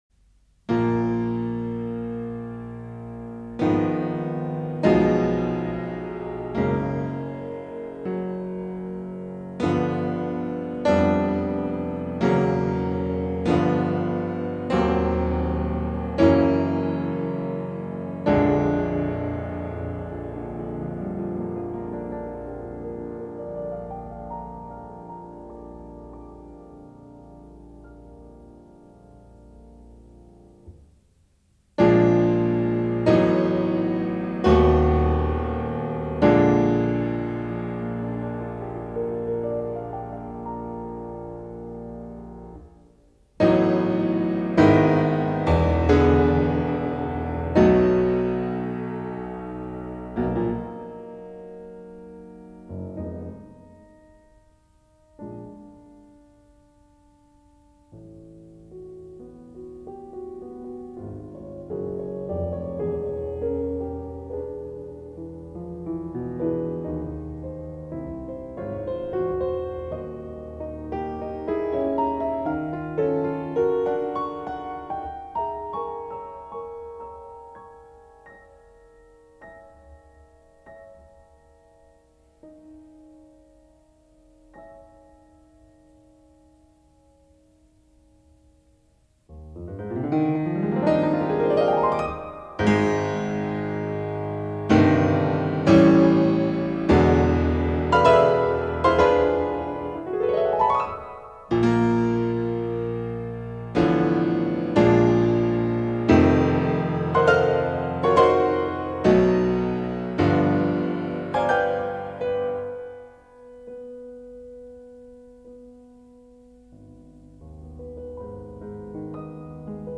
RNS2 (TRÈS LENT)
RNS2 Sonate Piano P. Dukas (Très lent)